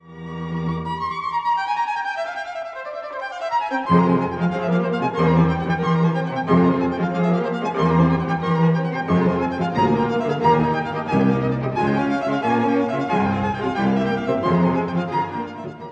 ↑古い録音のため聴きづらいかもしれません！（以下同様）
コーダでは、1stバイオリンが叫ぶように同じ音型を繰り返し、絶望で終わります。